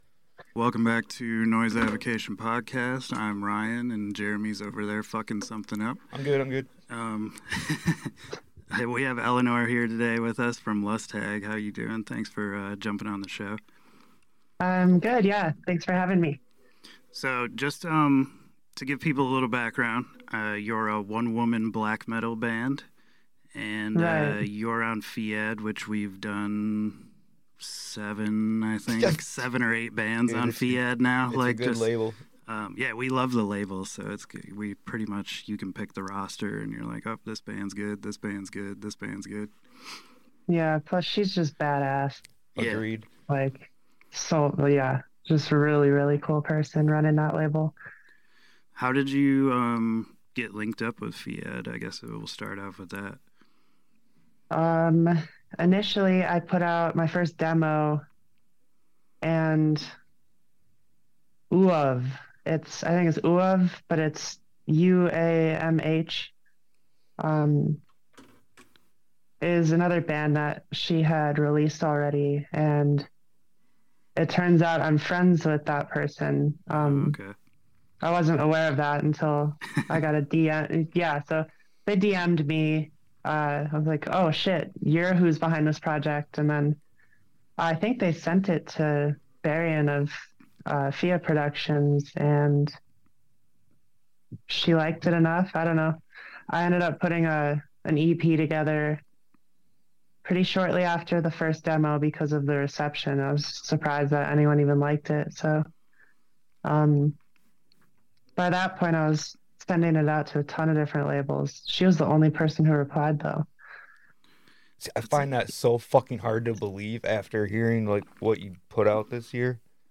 Checkout the interview to hear a bit about her writing process, artwork, music, and much more.